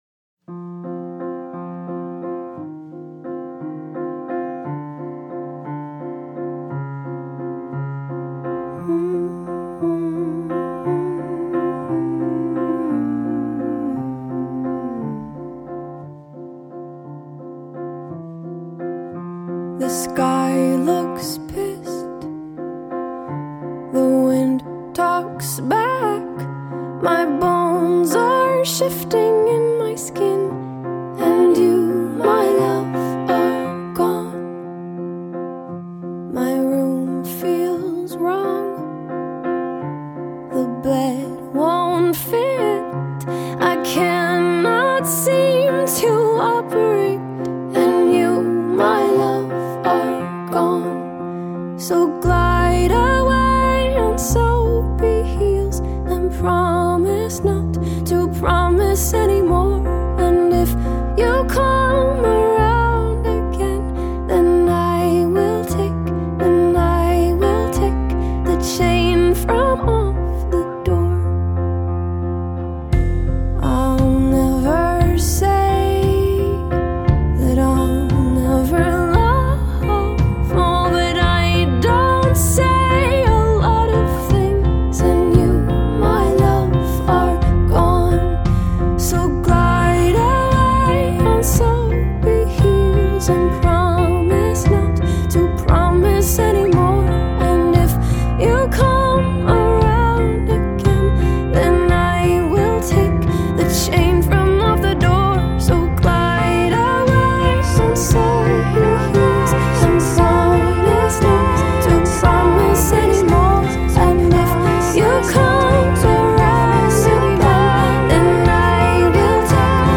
goes to show how studio recording itself can be an art form.